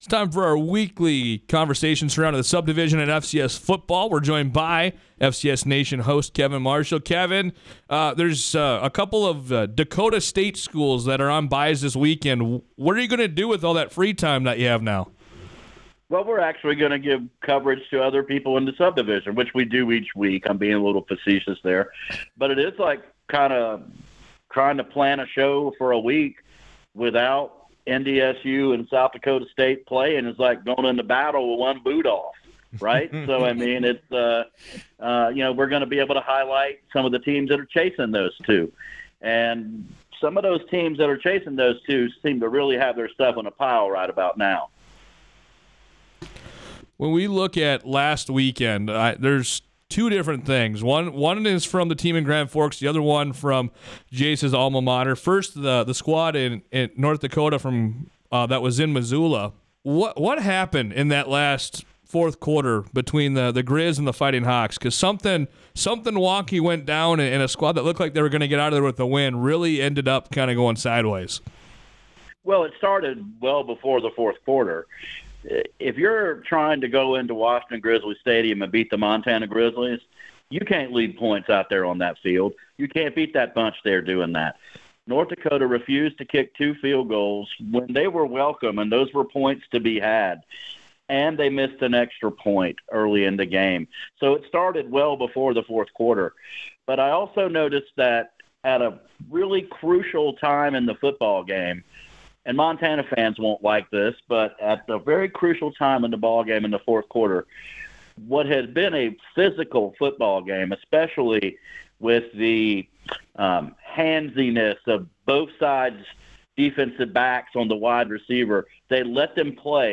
for their weekly conversation surrounding the subdivision. They recapped last weeks big game’s look ahead to some of the best matchups for this upcoming week, and more.